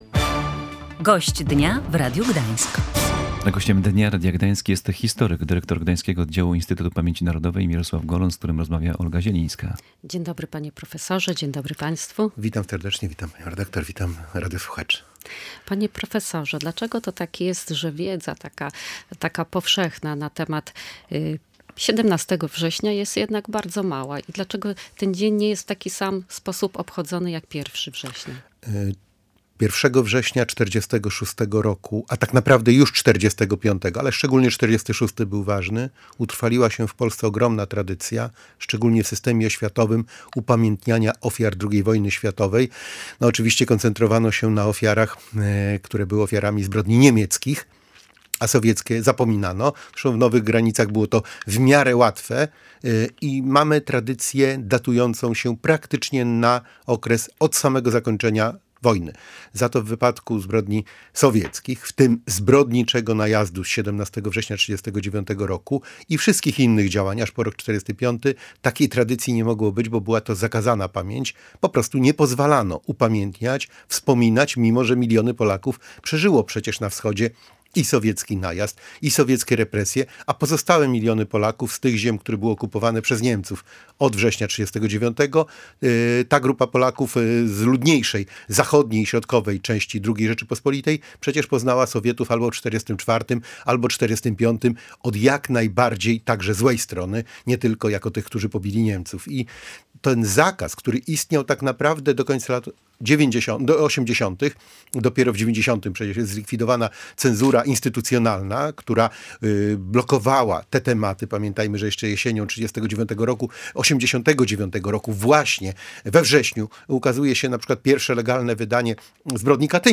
Gościem Dnia Radia Gdańsk był prof. Mirosław Golon, dyrektor Oddziału IPN w Gdańsku. Dlaczego Armia Czerwona zaatakowała Polskę dopiero 17 września? Jaka była reakcja Zachodu na agresję sowiecką? Jak ludność polska i innych narodowości na Kresach przyjmowała Armię Czerwoną?